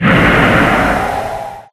Breath.ogg